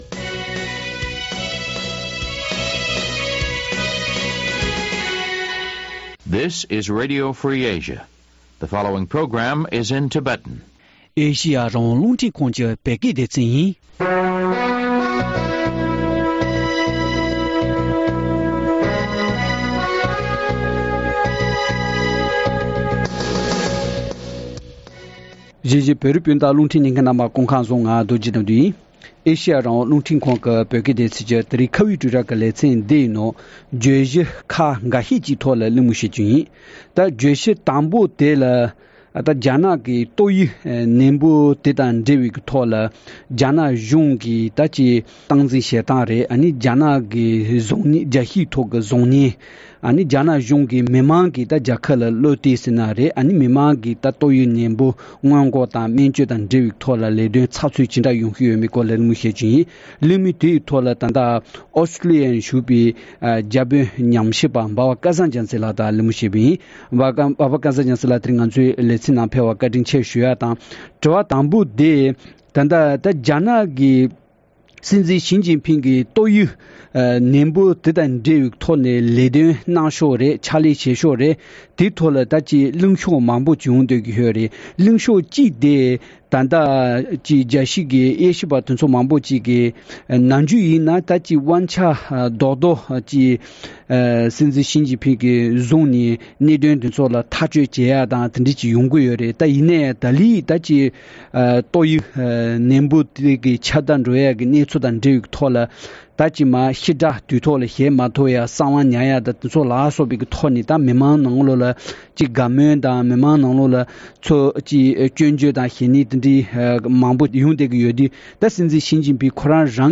དེ་བཞིན་ལེ་ཚན་དུམ་བུ་གཉིས་པའི་ནང་ཨ་རིའི་ནང་བོད་མི་སྐྱབས་བཅོལ་བའི་ས་ཁང་ཉོ་ཚོང་གི་དགེ་སྐྱོན་སྐོར་གླེང་མོལ་ཞུས་པ་བཅས་གསན་རོགས་གནང་།